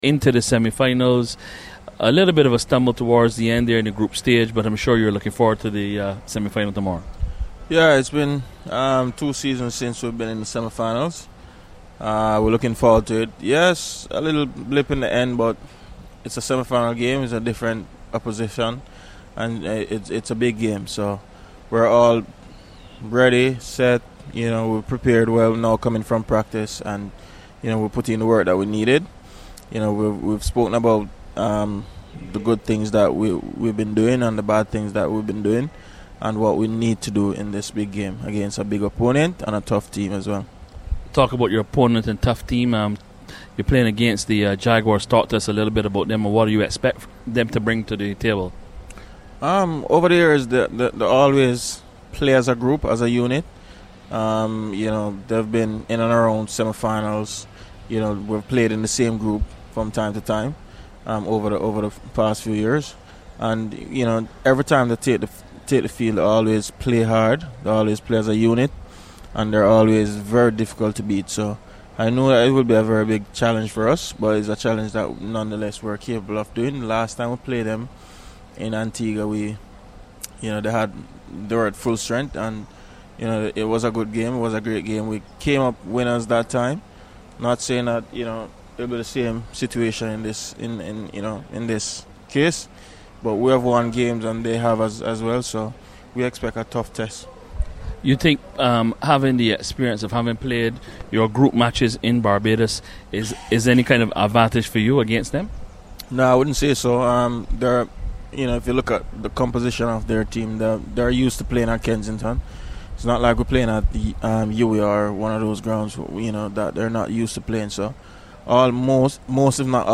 Jamaica Scorpions captain Nikita Miller spoke to CWI Media on the eve of the first semi-final